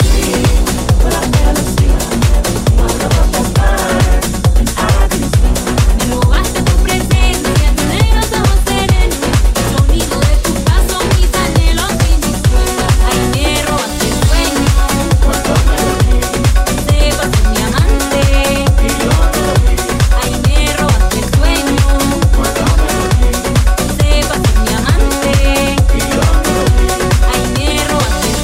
Genere: house,salsa,tribal,afro,tech,remix,hit